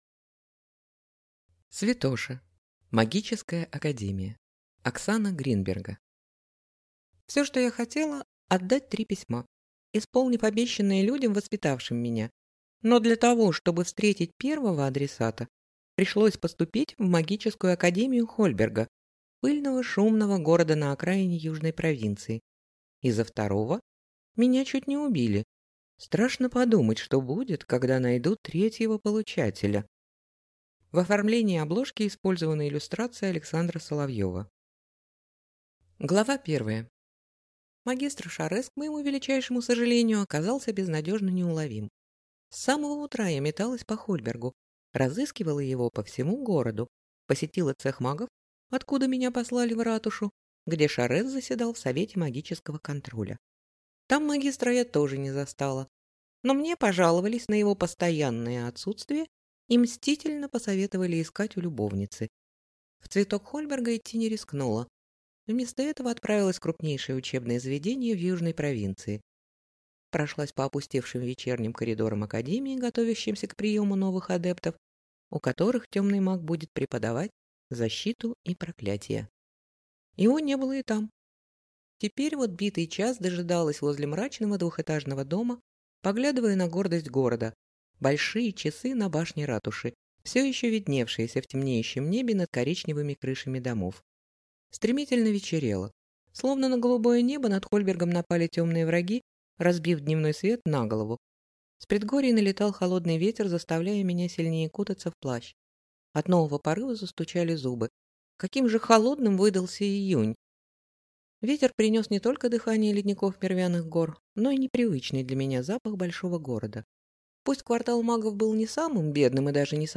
Аудиокнига Святоша. Магическая Академия | Библиотека аудиокниг